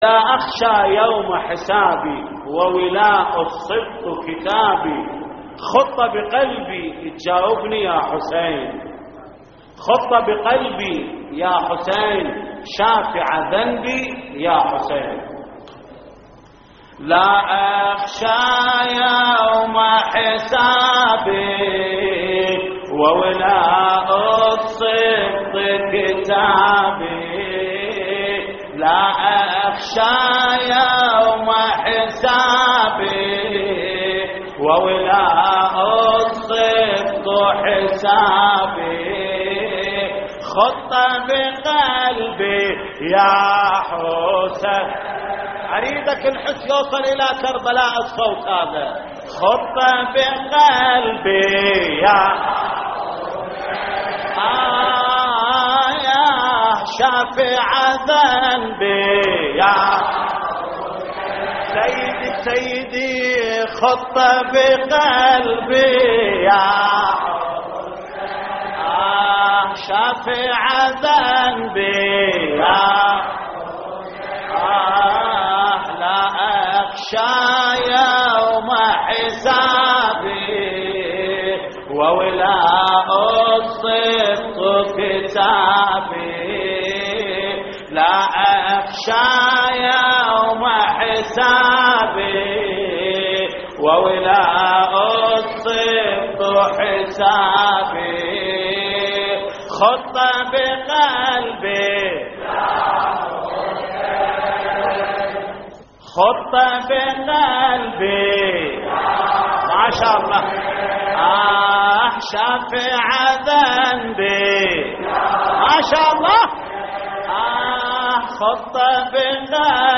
تحميل : لا أخشى يوم حسابي وولاء السبط كتابي / الرادود جليل الكربلائي / اللطميات الحسينية / موقع يا حسين